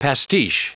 Prononciation du mot pastiche en anglais (fichier audio)
Prononciation du mot : pastiche